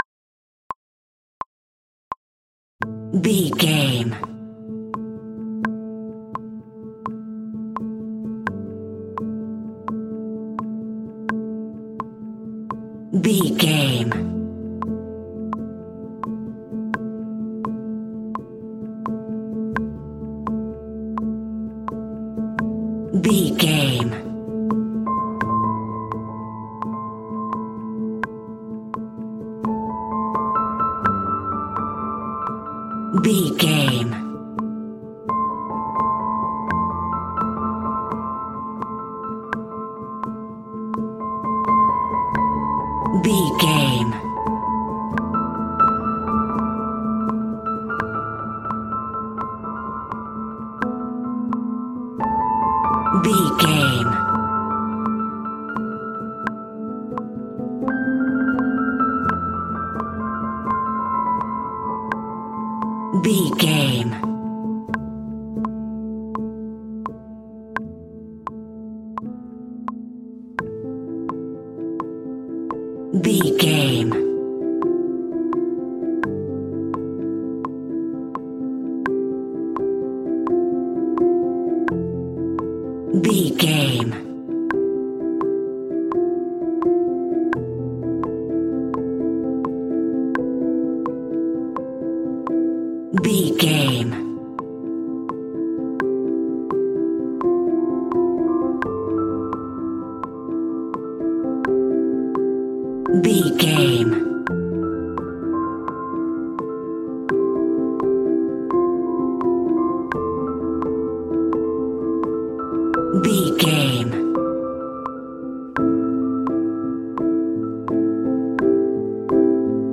Ionian/Major
tension
suspense
dark
synthesiser